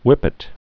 (wĭpĭt, hwĭp-)